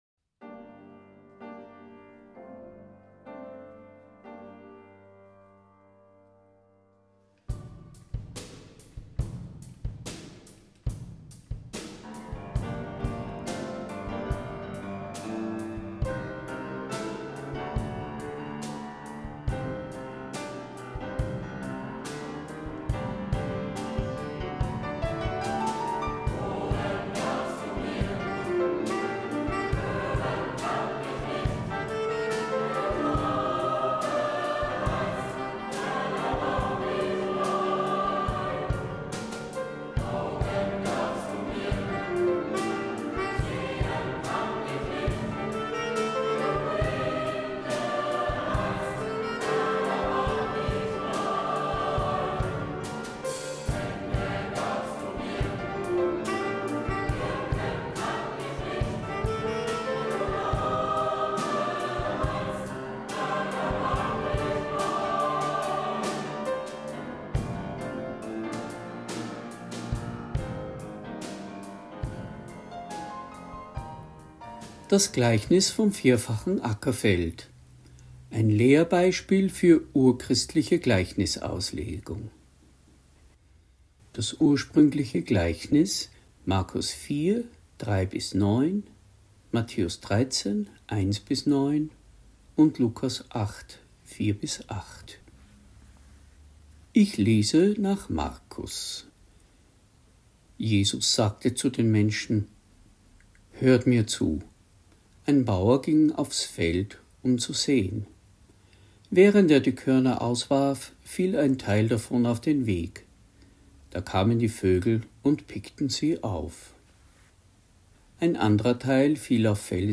Predigt | NT02 Markus 4,1-9 Das 4-fache Ackerfeld (1) Das Gleichnis